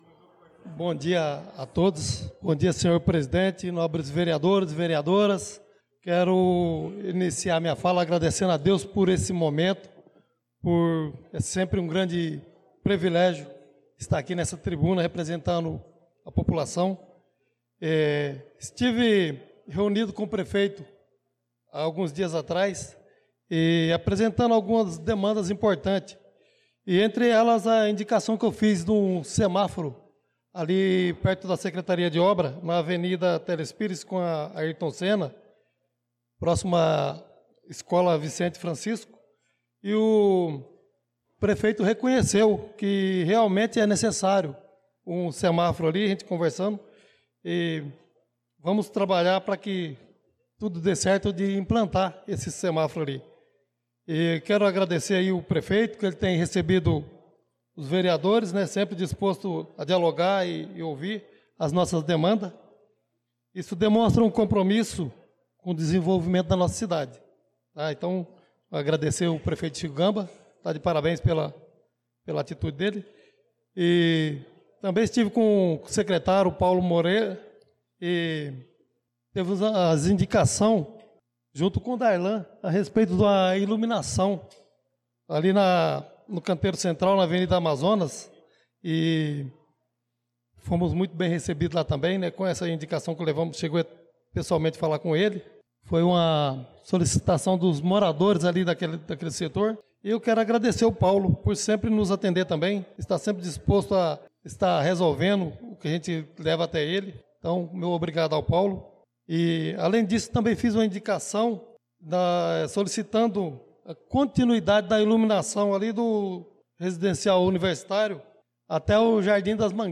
Pronunciamento do vereador Chicão Motocross na Sessão Ordinária do dia 06/03/2025